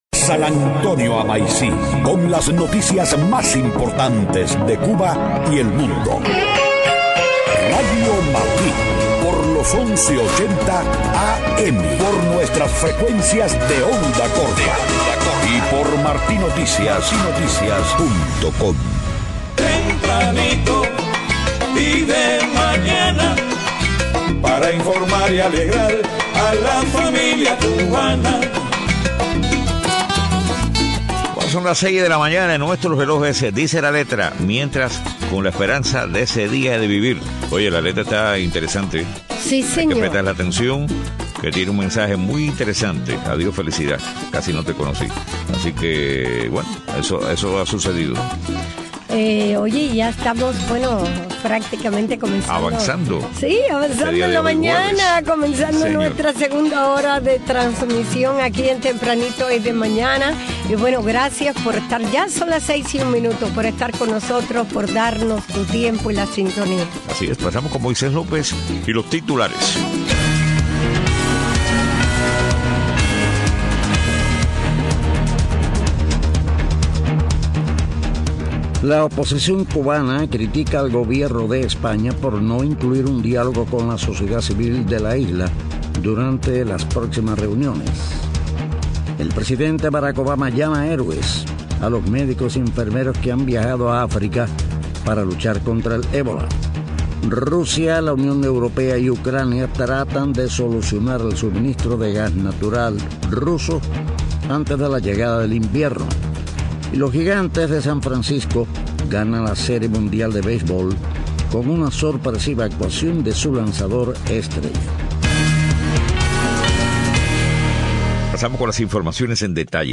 6:00 a.m. Noticias: Oposición cubana critica al Gobierno de España por no incluir un diálogo con la sociedad civil de la isla en las próximas reuniones. Presidente Obama llama héroes a los médicos y enfermeros que han viajado a África para luchar contra el ébola.